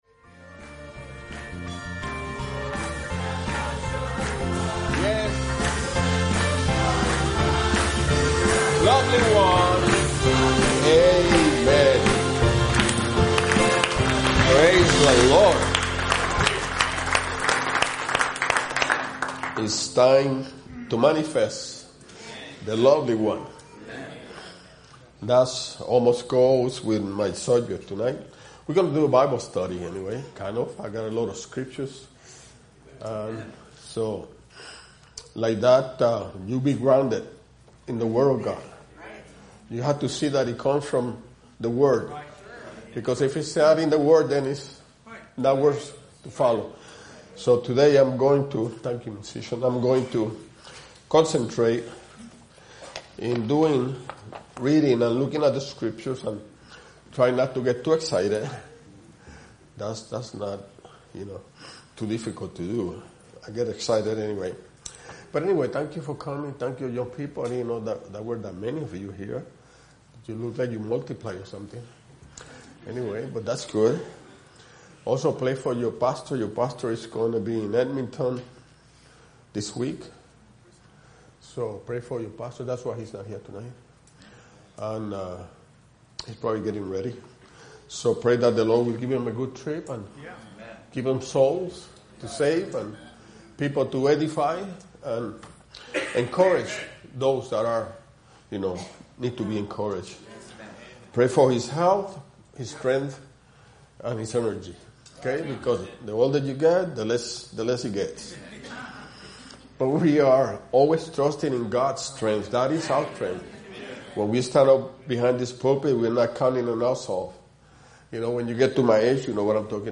Youth Meeting